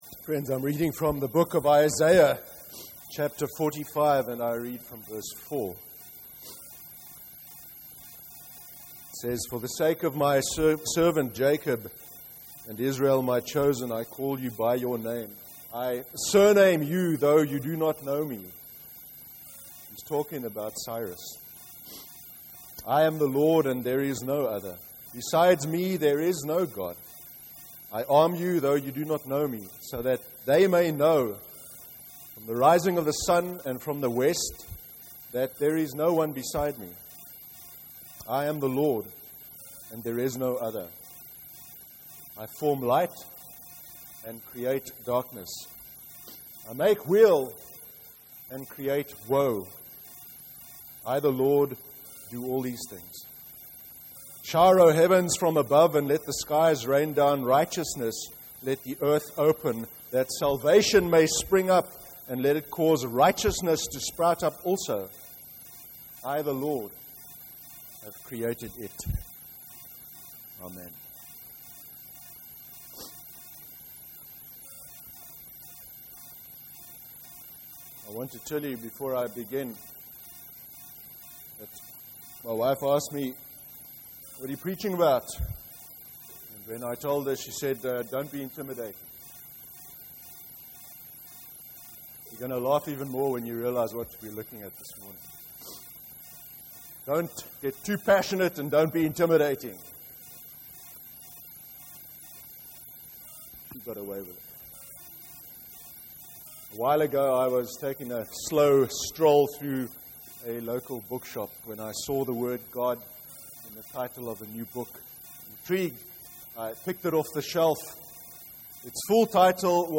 09/08/2015 sermon – Monotheism and the issue with dualism (Isaiah 45:4-8)